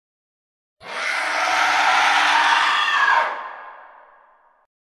jumpscarefinal.mp3